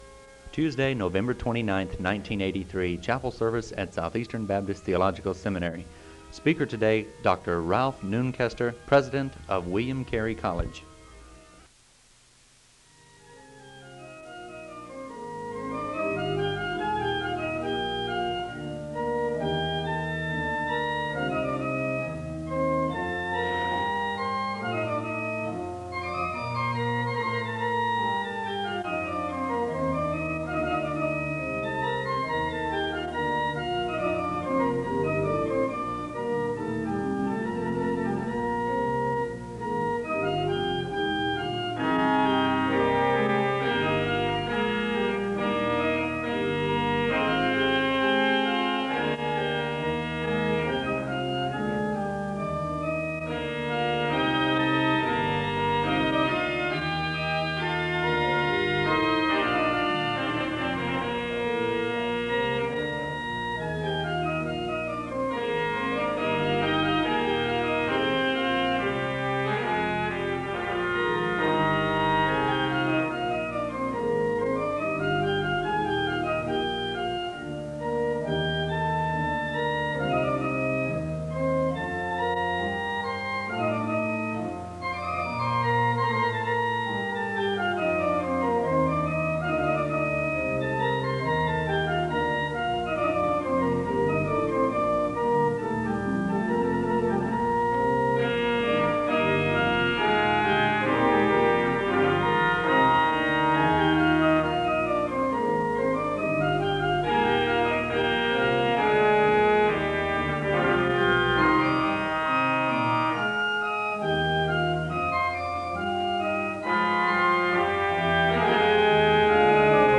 The service begins with organ music (00:00-03:11). The speaker leads the audience in a responsive reading, and he gives a word of prayer (03:12-04:40).
The choir sings the anthem (06:42-09:59).
The service ends with a benediction (42:02-42:40).
In Collection: SEBTS Chapel and Special Event Recordings SEBTS Chapel and Special Event Recordings